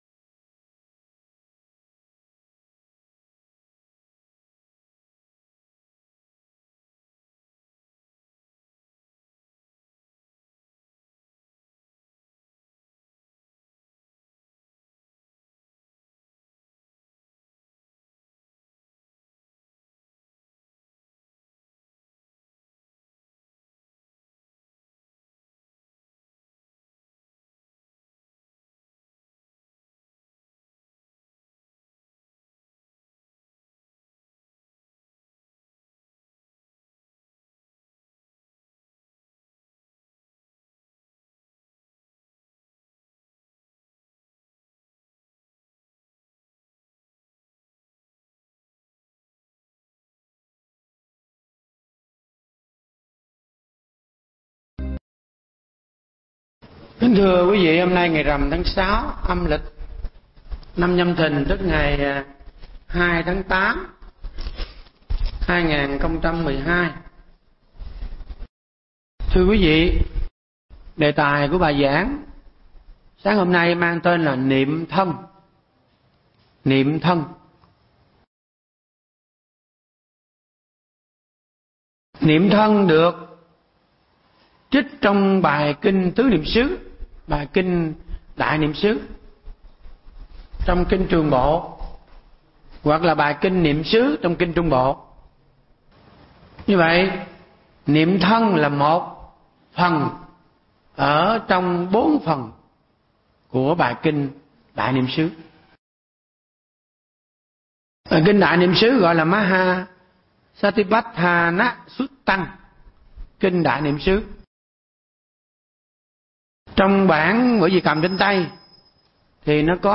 Nghe Mp3 thuyết pháp Niệm Thân